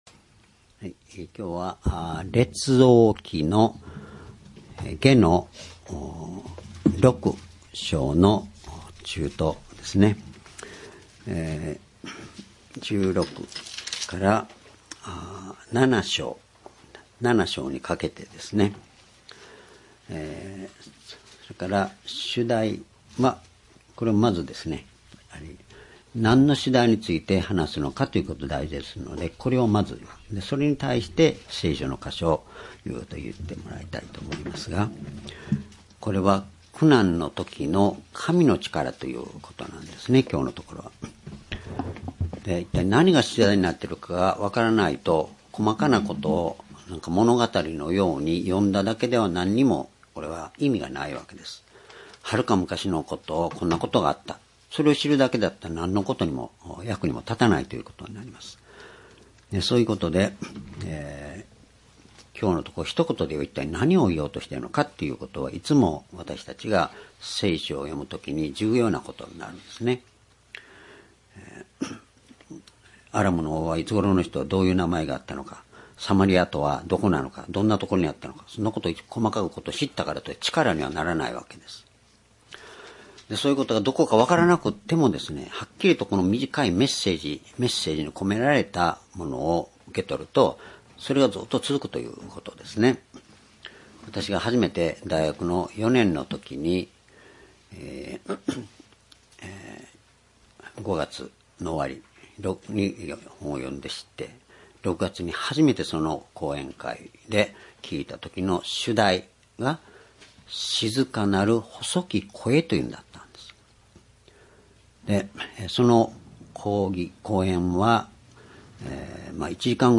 （主日・夕拝）礼拝日時 2019年4月7日 主日 聖書講話箇所 「苦難の時の神の力」 列王記下6章16節～7章11節 ※視聴できない場合は をクリックしてください。